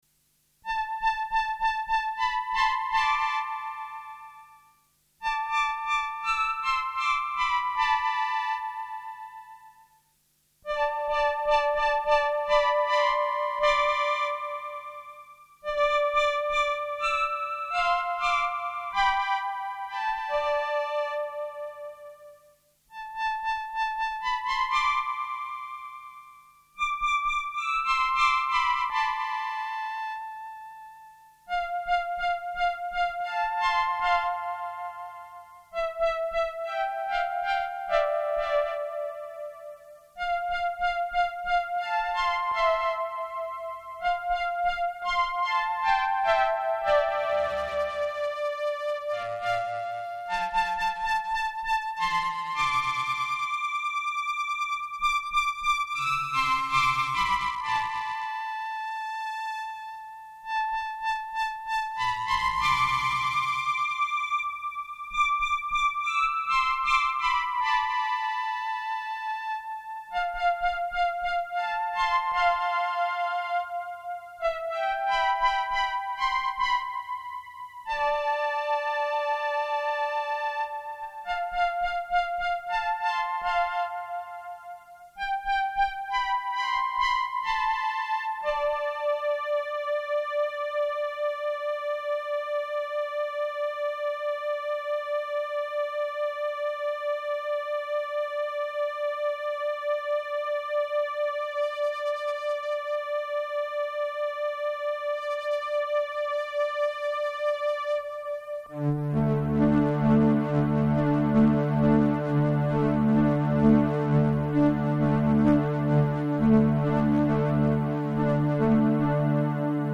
/4) Modifications/Chorus Feedback mod/Poly800mod-chorus.mp3Chorus - added feedback potentiometer2.1 MB
Poly800mod-chorus.mp3